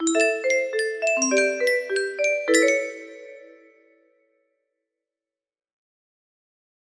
Down transpose so my musicbox play it right